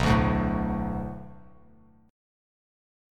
Bm7 Chord
Listen to Bm7 strummed